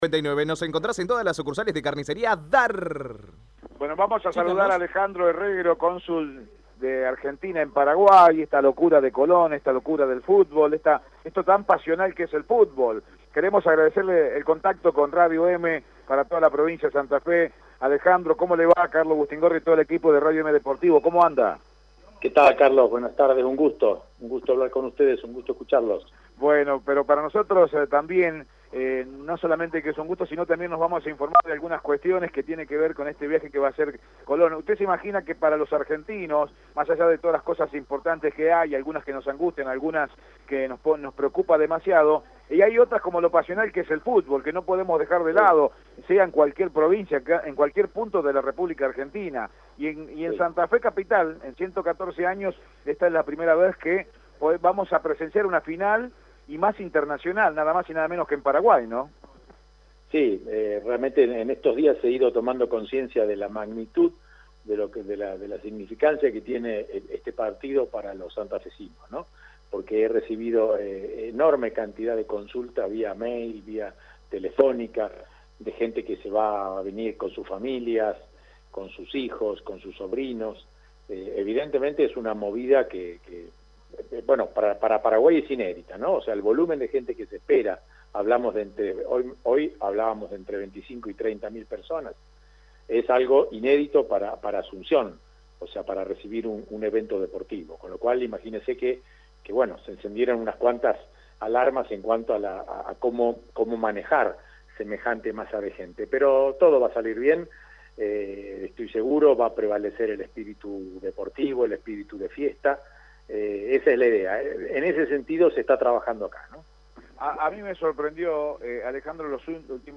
En la previa del encuentro de Colón contra Estudiantes de Buenos Aires, en Radio Eme Deportivo hablamos con el Cónsul Argentino en Paraguay, Alejandro Herrero, que brindó datos importantes sobre el operativo de seguridad para la final e información para los hinchas del sabalero que viajarán a Asunción.